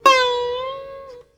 SITAR LINE64.wav